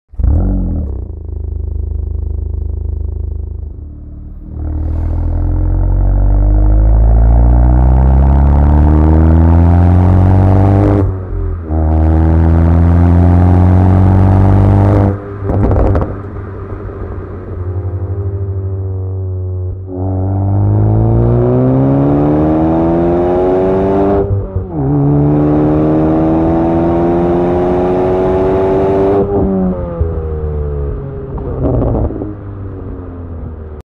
Sound Check Hyundai i20N with sound effects free download
Sound Check Hyundai i20N with REMUS Sport Exhaust🔥